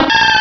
-Replaced the Gen. 1 to 3 cries with BW2 rips.
bayleef.aif